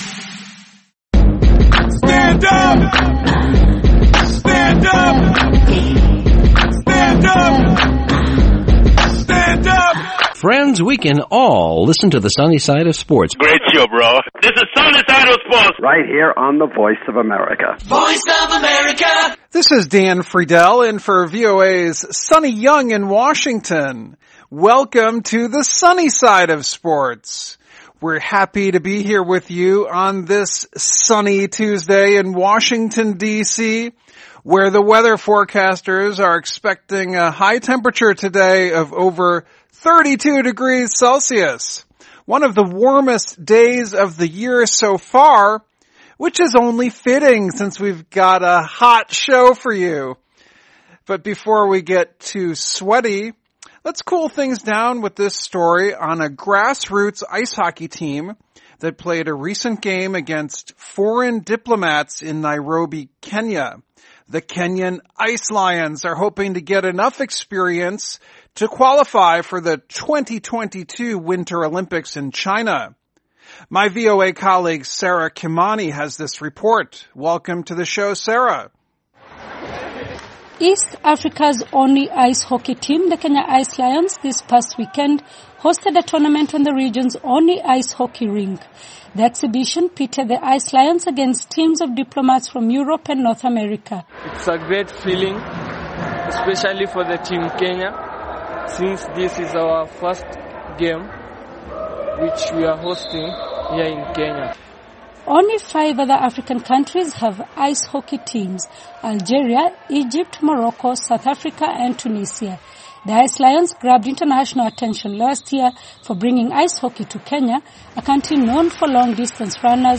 Airing Monday through Friday, this 30-minute program takes a closer look at the stories Africans are talking about, with reports from VOA correspondents, and interviews with top experts and...